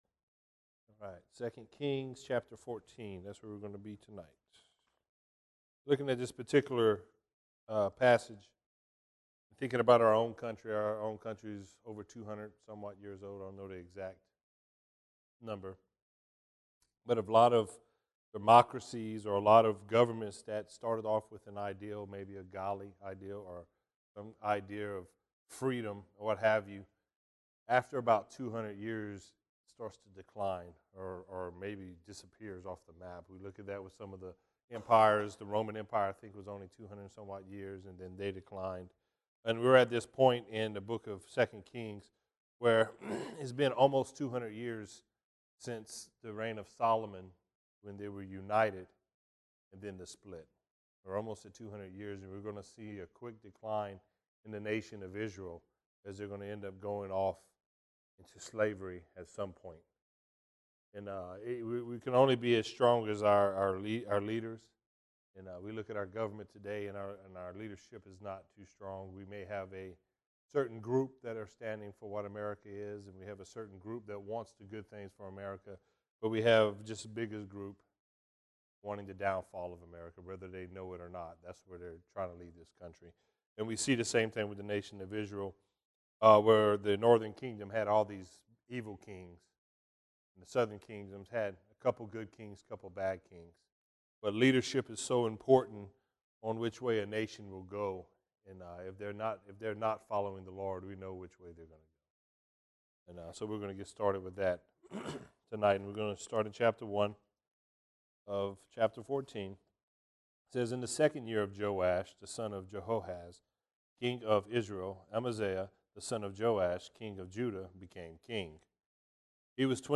verse by verse study